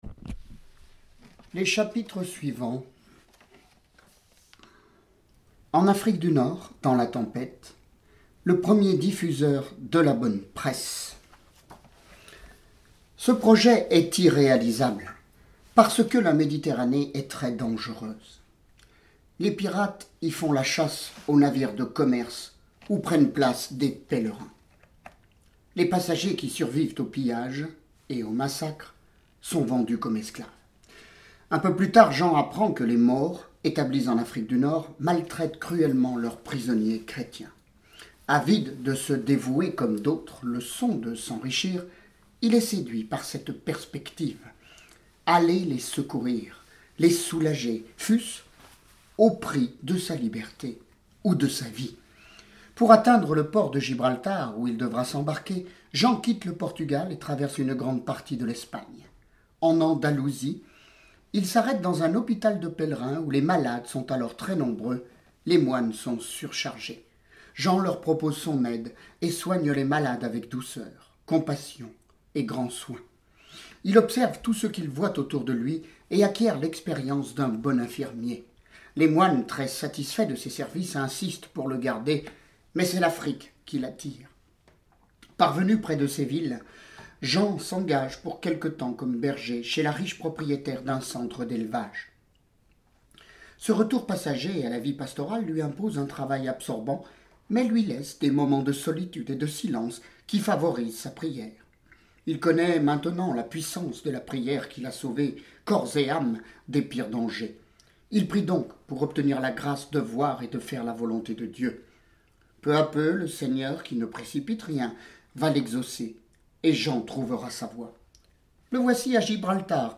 Lecture de vies de Saints et Saintes